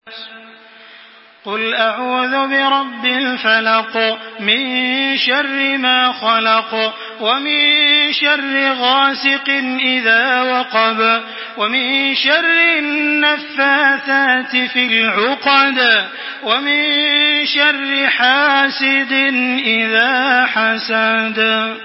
Makkah Taraweeh 1425
Murattal